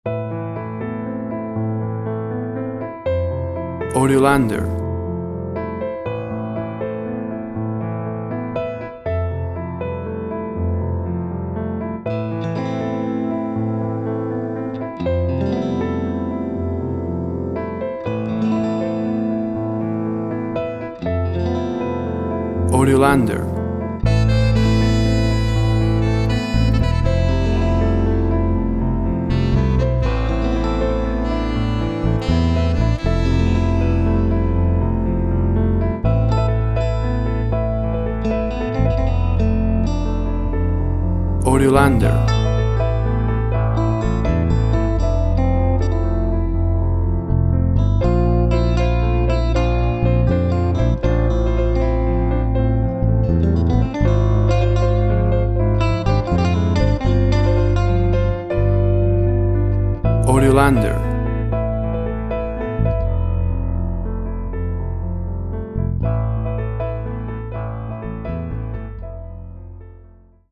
Musci for relax and Contemplative.
Tempo (BPM) 120